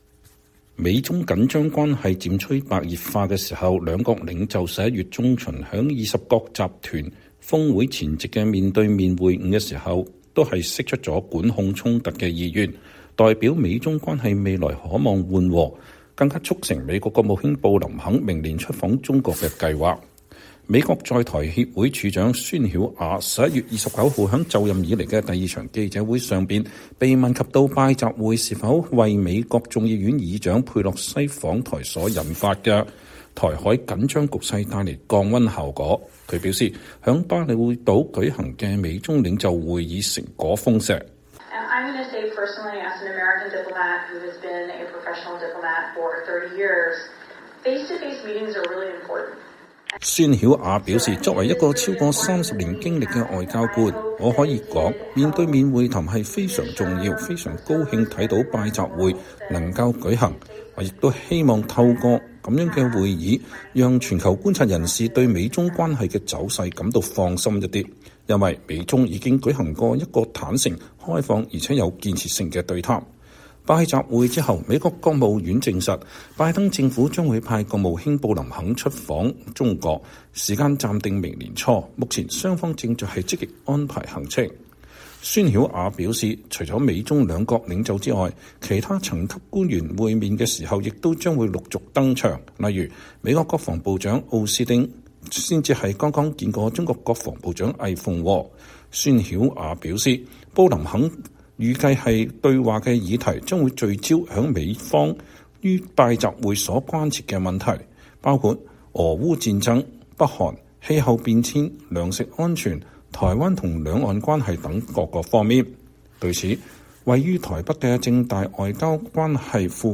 美國在台協會（AIT）處長孫曉雅（Sandra Oudkirk）週二（11月29日）召開記者會表示，“拜習會”已讓各界對美中關係的走緩吃下定心丸，而除了兩國領袖的會晤以外，美中其他層級的官員也將安排會面，顯示“避戰”是各國的共同責任。